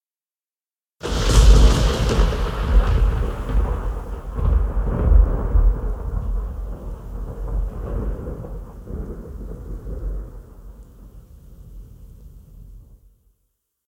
AS-SFX-Thunder 8.ogg